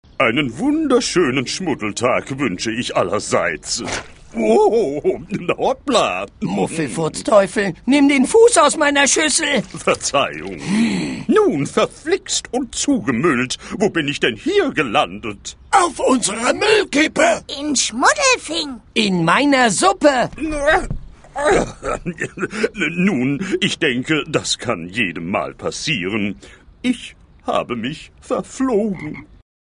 Szenische Lesung